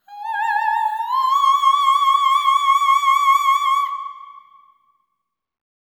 OPERATIC15-R.wav